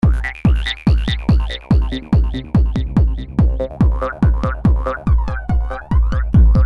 Qui potrete trovare files in formato .rbs e .wav da usare in modo loop, per poterli edittare, trasformare, oppure elaborare in sequenza con programmi appropriati, che sicuramente, gli appassionati a questo tipo di lavoro conoscono molto bene.